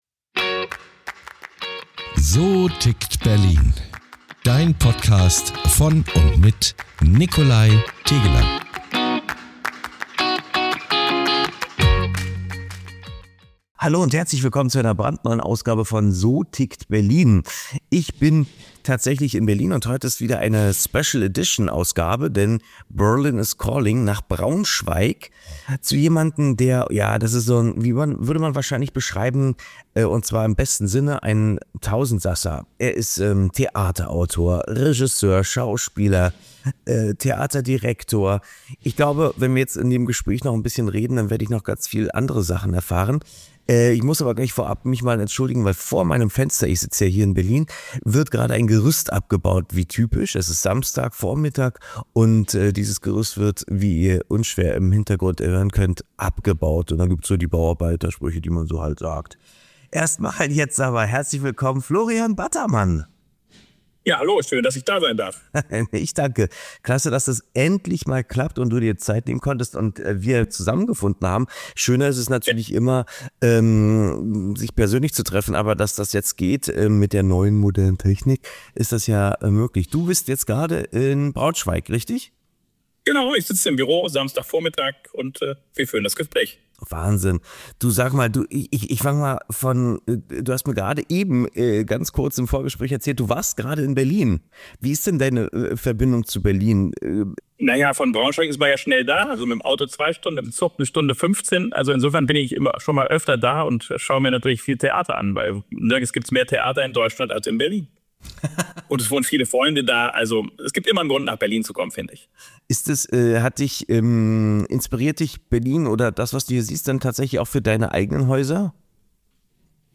Ein Gespräch über Kunst und Kommerz, über Verantwortung fürs Publikum – und darüber, wie man Theater nicht nur spielt, sondern lebt.